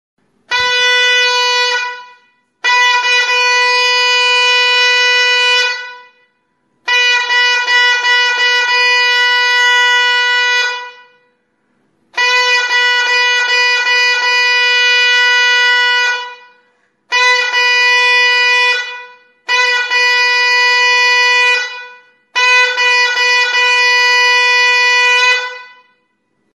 Music instrumentsKORNETA; TURUTA; HARROBIETAKO KORNETA; CORNETA DE CANTERO
Aerophones -> Reeds -> Single fixed (clarinet)
Recorded with this music instrument.
Letoizko adar itxura duen mihi bakarreko aerofonoa da. Nota bakarra ematen du.